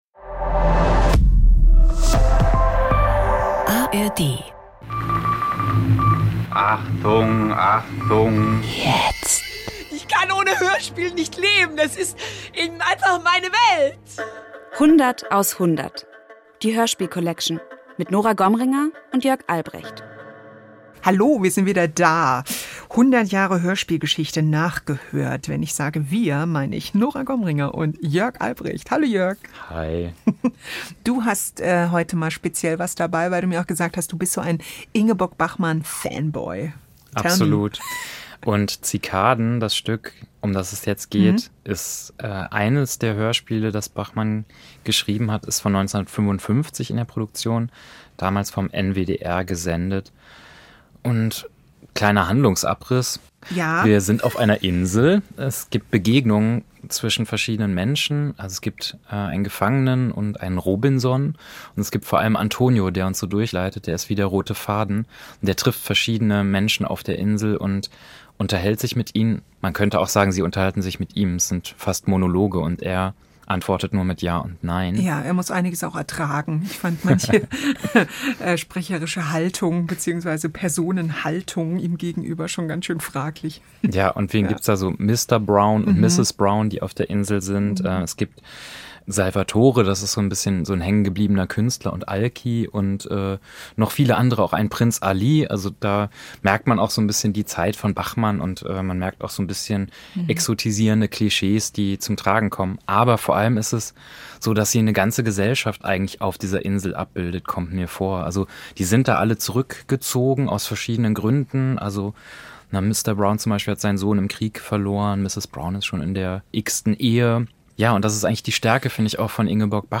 Ingeborg Bachmann ha creato una forma letteraria indipendente con i suoi epici radiodrammi originali.